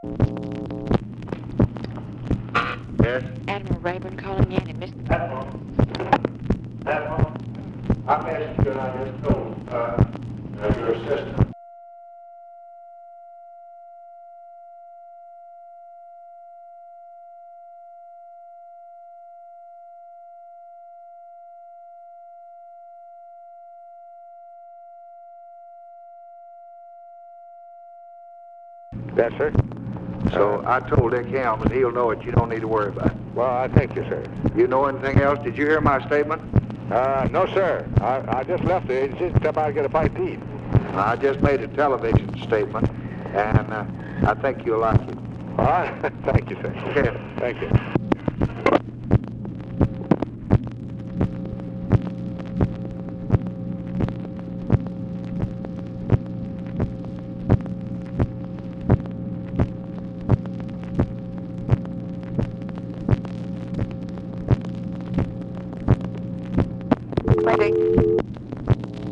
RABORN RETURNS LBJ'S TELEPHONE CALL; LBJ TELLS RABORN THAT HE HAS TOLD RICHARD HELMS TO UNDERTAKE INVESTIGATION; LBJ'S RECENT STATEMENT ON DOMINICAN CRISIS
Secret White House Tapes